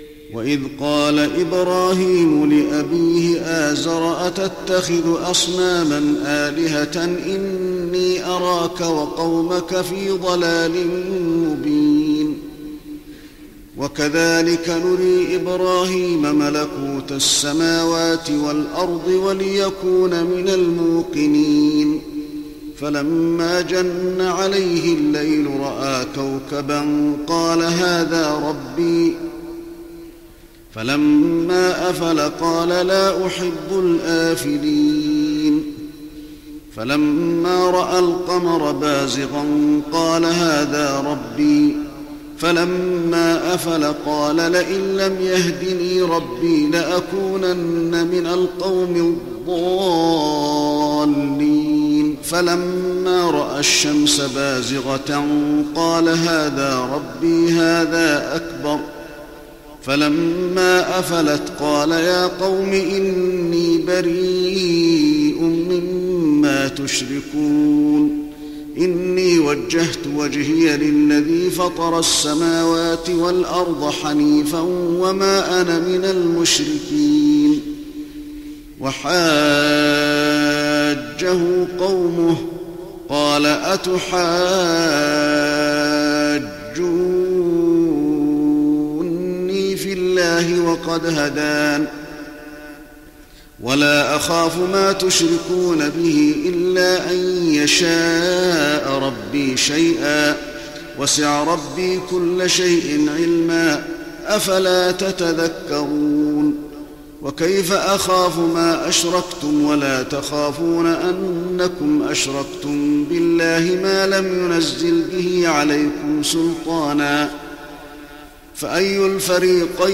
تهجد رمضان 1415هـ من سورة الأنعام (74-127) Tahajjud Ramadan 1415H from Surah Al-An’aam > تراويح الحرم النبوي عام 1415 🕌 > التراويح - تلاوات الحرمين